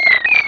Cri d'Azurill dans Pokémon Rubis et Saphir.